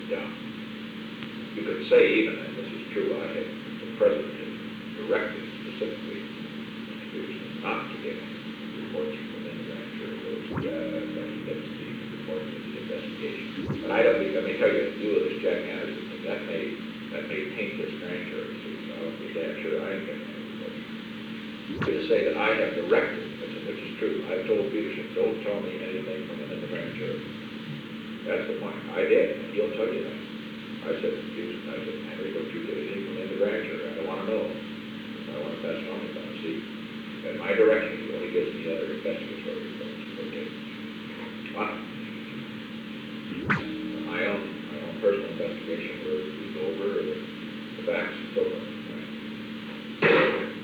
Secret White House Tapes
Conversation No. 429-5
Location: Executive Office Building
The President talked with Ronald L. Ziegler.